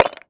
metal_small_movement_12.wav